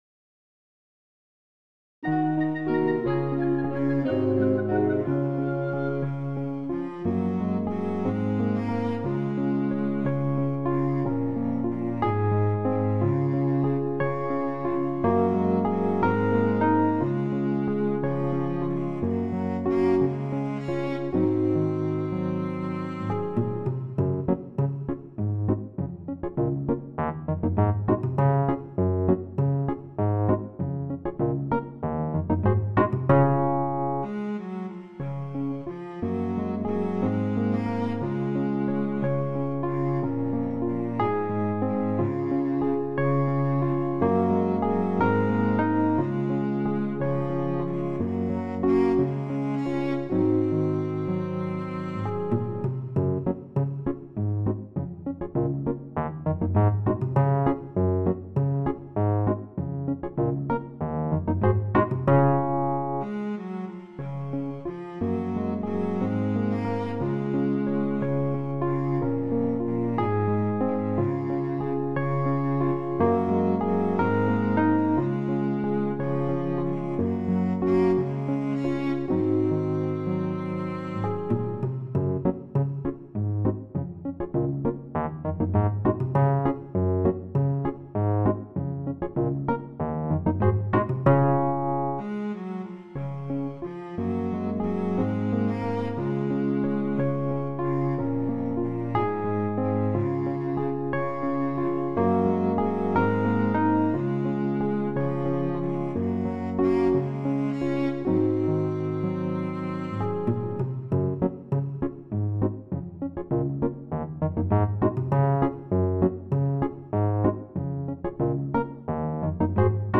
Accompagnement seul :